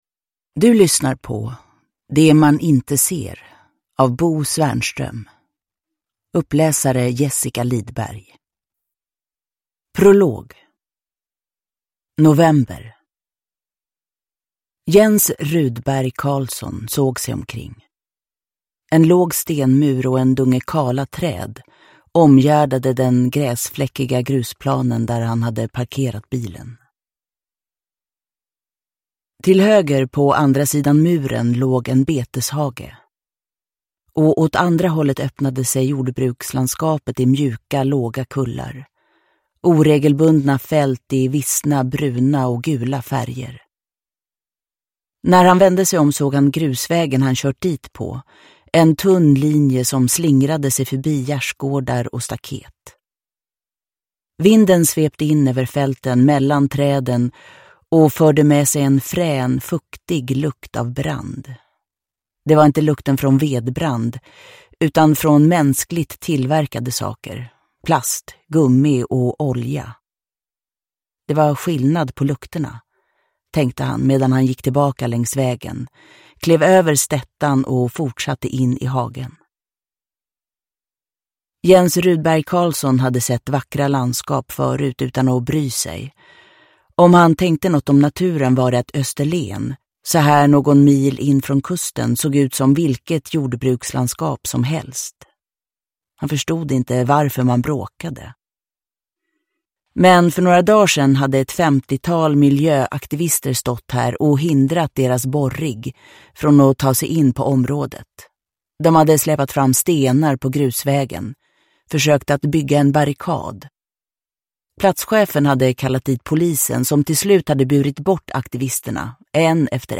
Uppläsare: Jessica Liedberg
Ljudbok